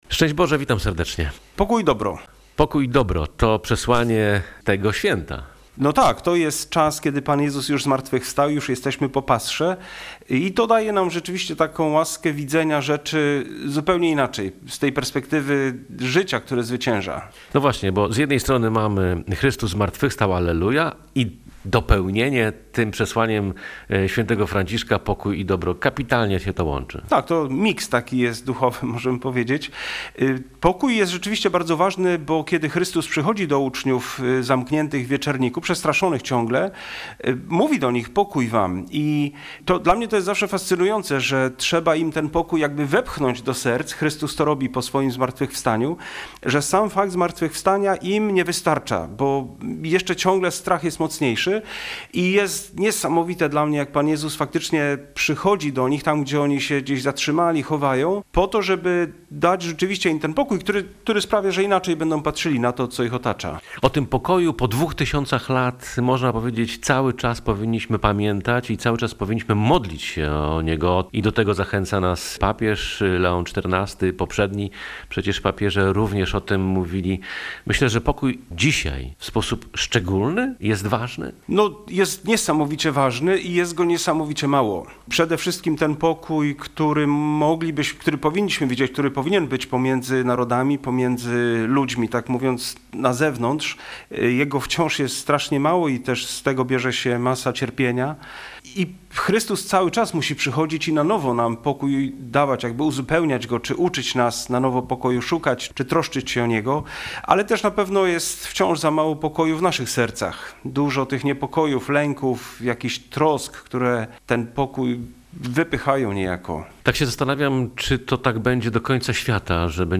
Nie pozwólmy świętom umrzeć. Posłuchaj rozmowy o tym, co najważniejsze